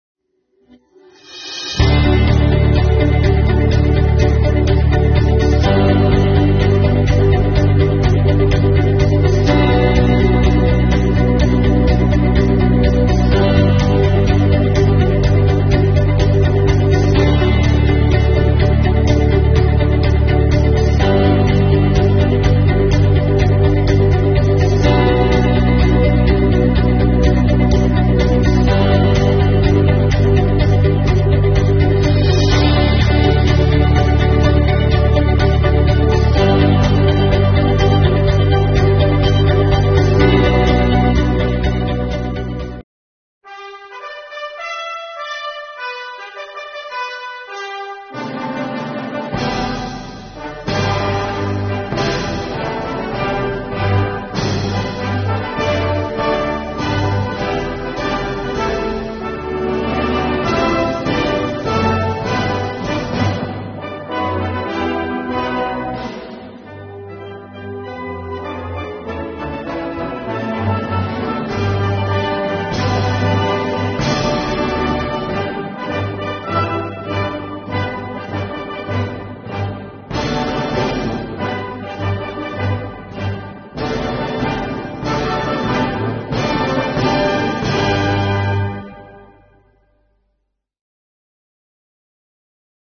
10月8日上午，辽源市税务局举行升国旗仪式，共同庆祝中华人民共和国成立72周年，以更加昂扬的精神面貌迎接四季度工作的开始。
上午9时，升国旗仪式正式开始，伴随着嘹亮的口号声，护旗手迈着整齐的步伐，庄严走向旗台。
全体税务干部面对国旗肃立致敬，行注目礼，高唱国歌，表达对党和祖国的无限忠诚，衷心祝愿祖国更加繁荣富强，人民幸福安康！